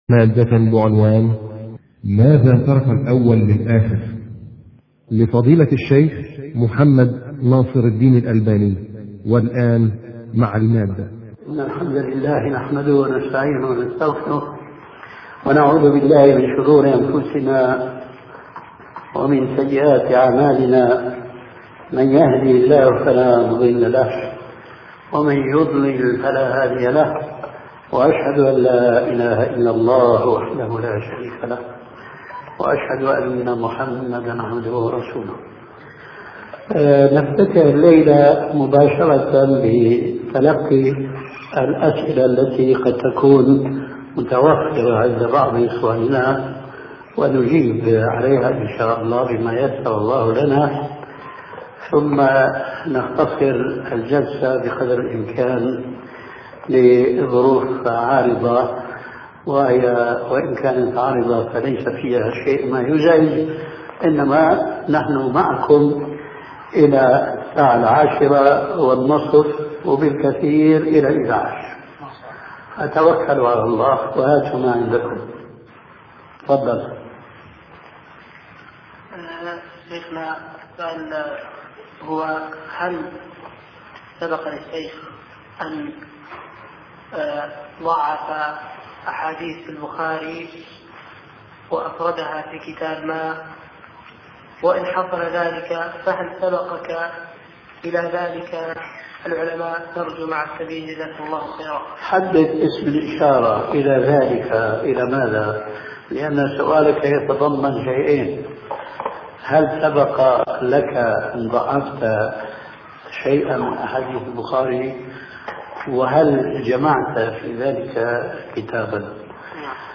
شبكة المعرفة الإسلامية | الدروس | ماذا ترك الأول للآخر؟